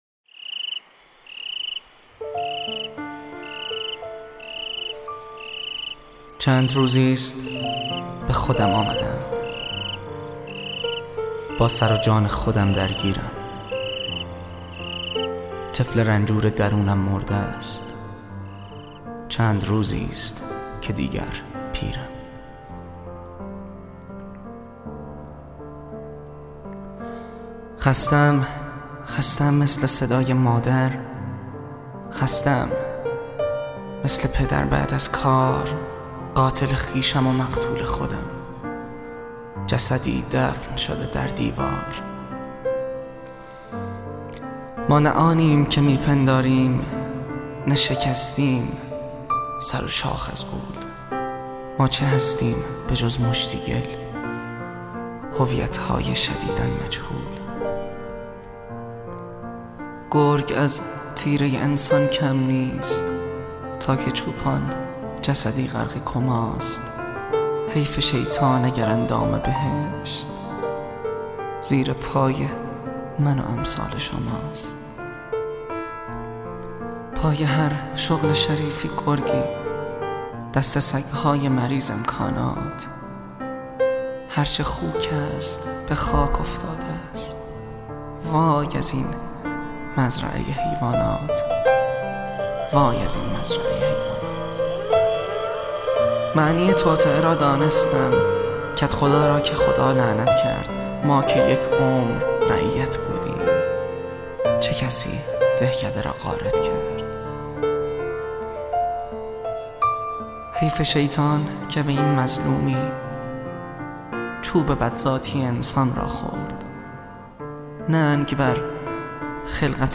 دکلمه